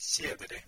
Ääntäminen
IPA : /ˈdʌl/